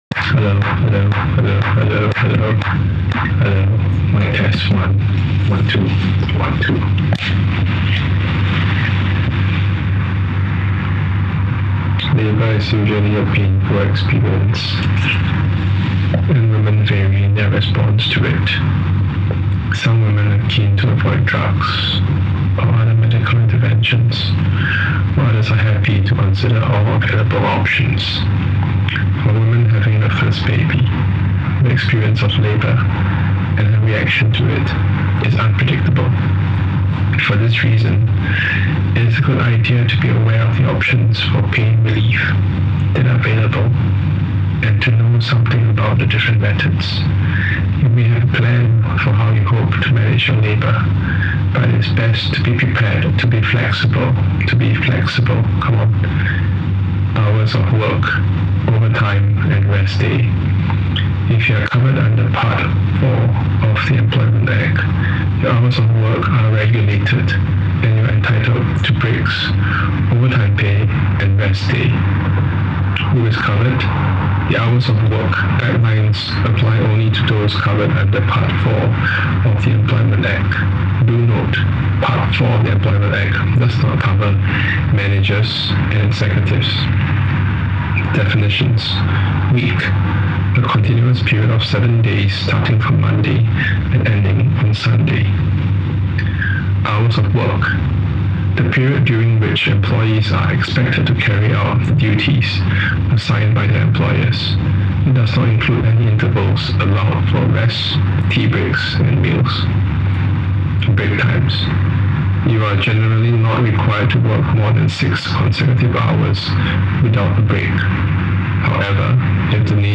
Work? questions the inherent relationships between man and woman, man and machine, and man and systems of power and authority in a workplace. Through a muting of the visuals, TAV overlayed a mechanised voice that readout instructions, labour laws, and narratives serendipitously encountered in the workplace.
As a performative gesture, ‘workers’ from TAC disrupts the video installation and plays the spoken voice piece to an unsuspecting audience, reverting the space to its gallery state shortly after the mechanised voice ceases.